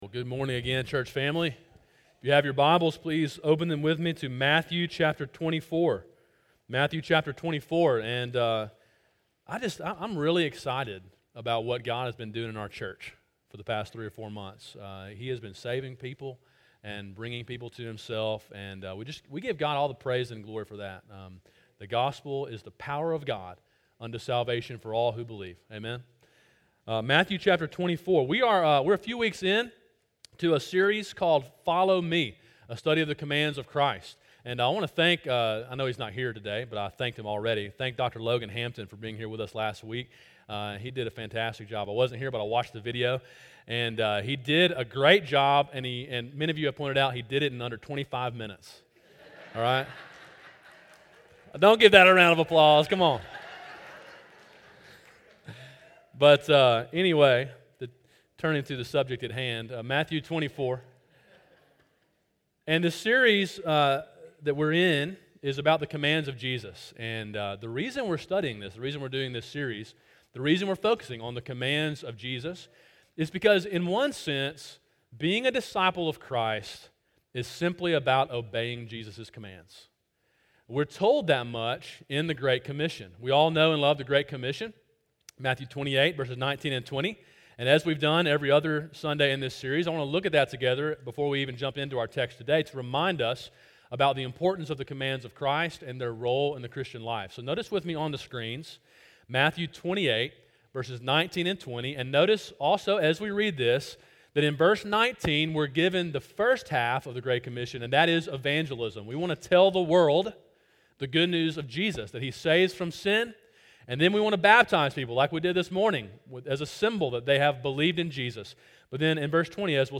Sermon: “Stay Awake” (Matthew 24:36-51) – Calvary Baptist Church